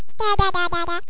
mumble2.mp3